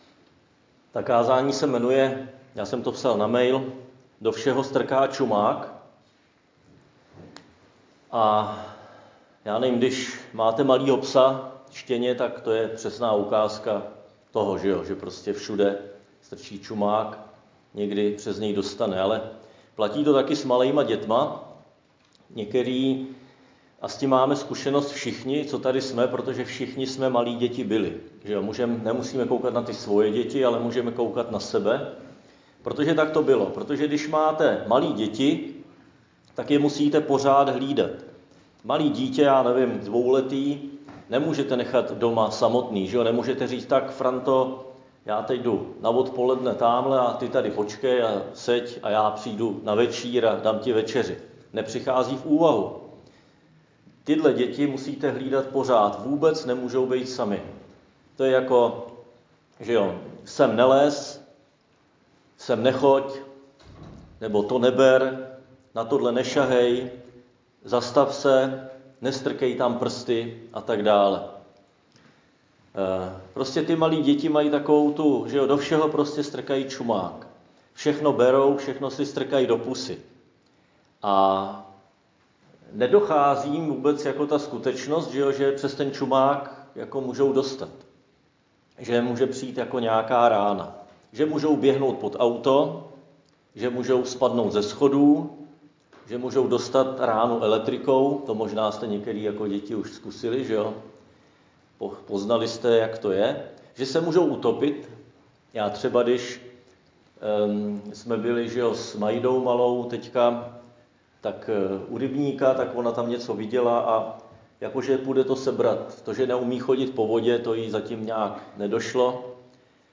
Křesťanské společenství Jičín - Kázání 28.3.2021